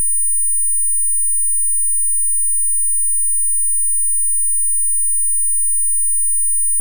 Here's a 2 channel 96kHz WAV that should be playable from a PC.
With ~9.6KHz and its harmanoics.
On my side, even with your updated audio source file, it is a clipped square wave.
From your audio source, firstly, DC component can be found as well as 9.6K and its 2rd, 3rd, 4rd harmonics